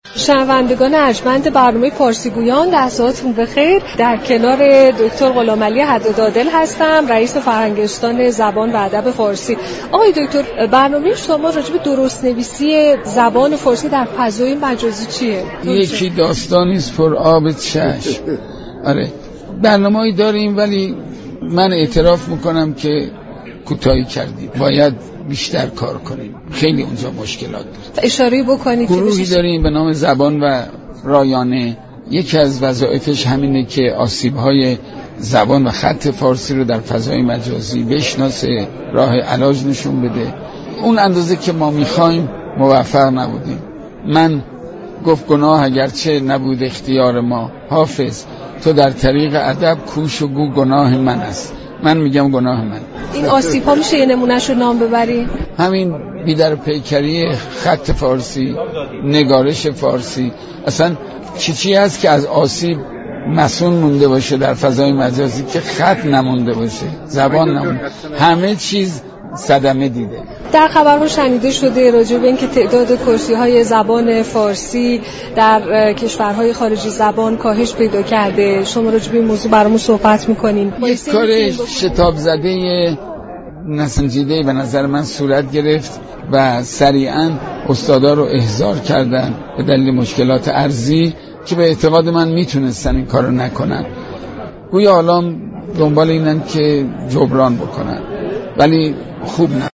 رئیس فرهنگستان زبان و ادب فارسی در گفت و گوی اختصاصی با گزارشگر رادیو ایران گفت: بی حساب و كتاب بودن خط و نگارش فارسی و هر آنچه مرتبط با زبان پارسی است؛ این روزها در فضای مجازی آسیب هایی را ایجاد كرده است.